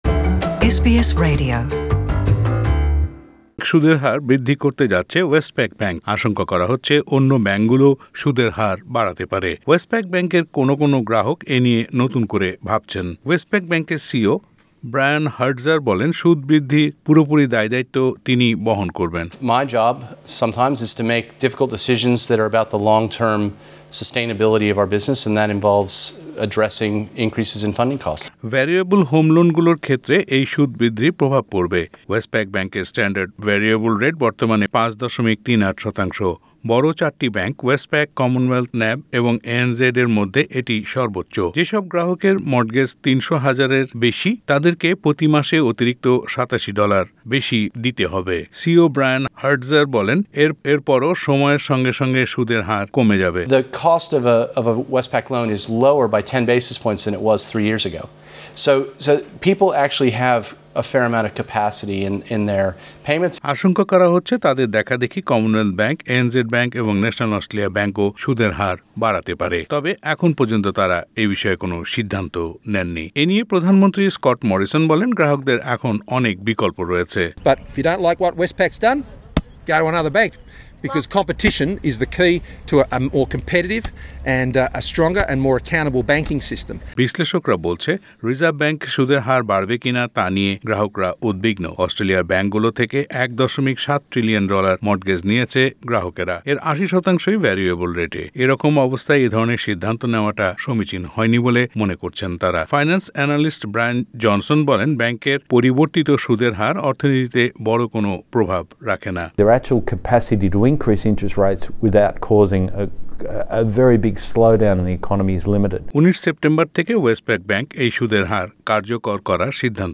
Listen to this report (in Bangla) in the audio player above.